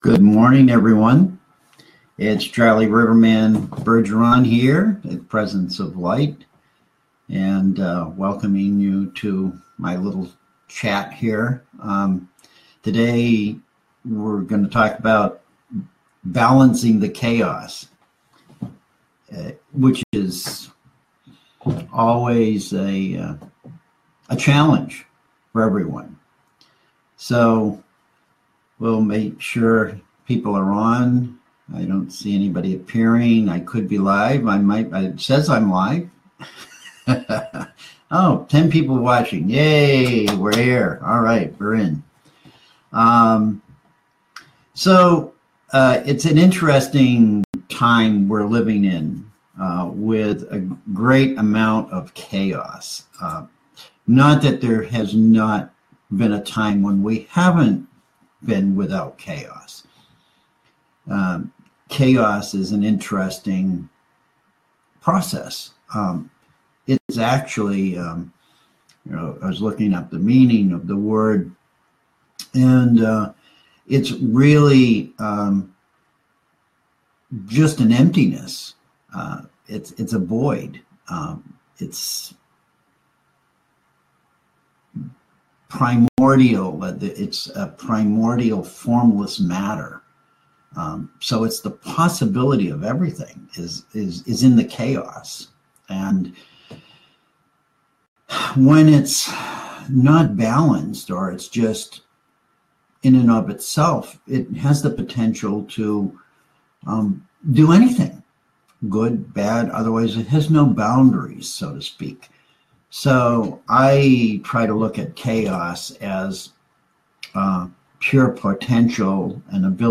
The video for the show is not posted but replaced by the audio file, as chaos prevailed in the video being scrambled after the first 5 minutes.